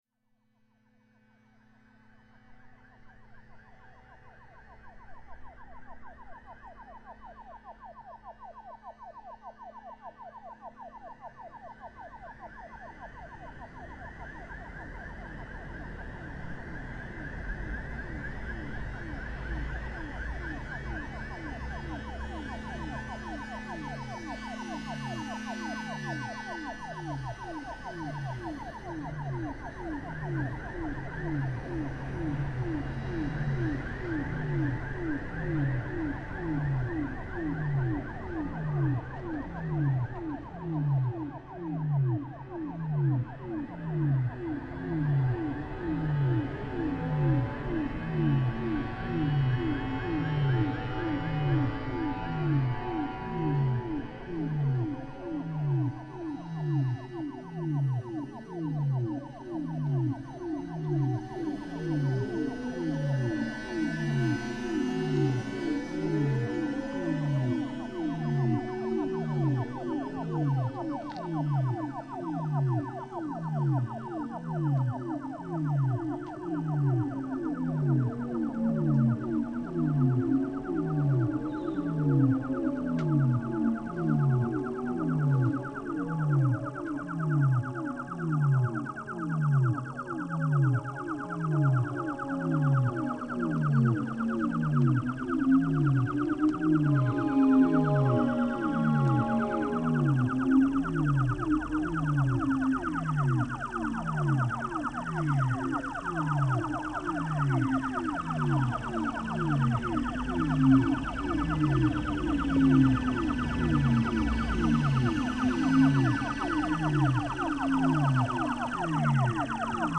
Crickets in Scopello, Sicily reimagined